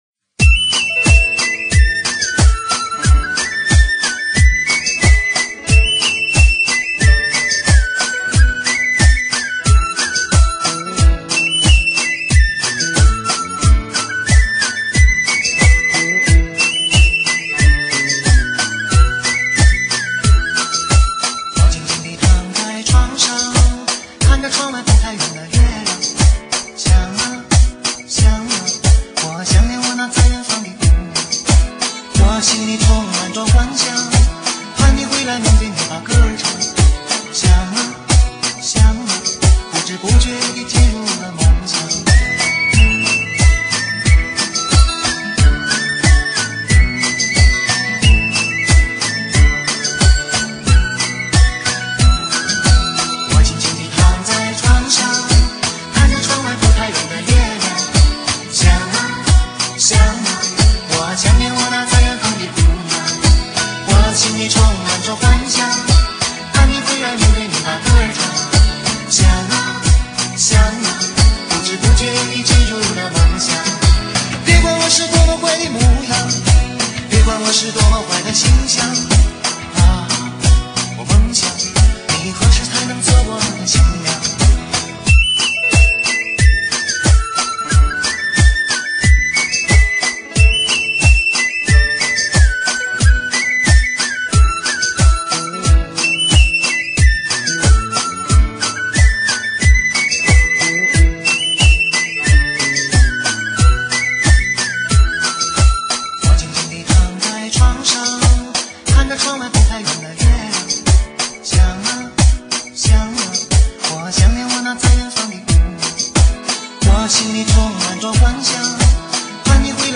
[4/6/2009]求高音质［吉特巴（小拉）］舞曲